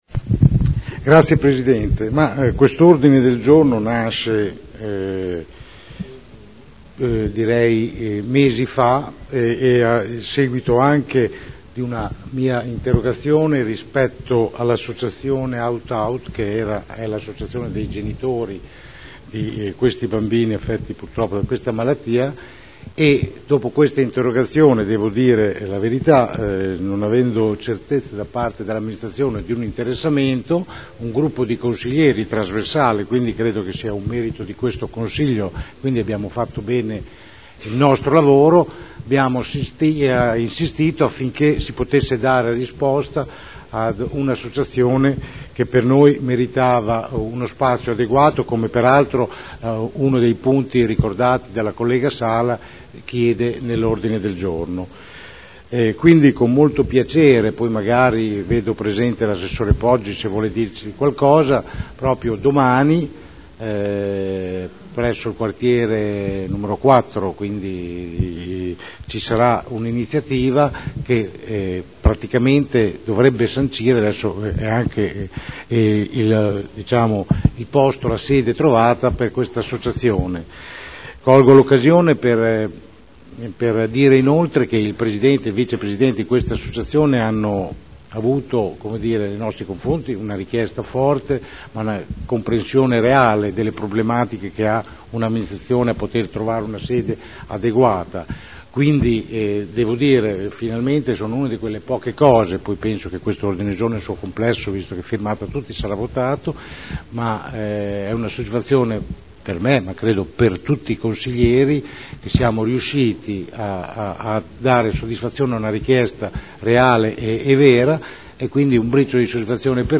Seduta del 16 aprile.
Dichiarazioni di voto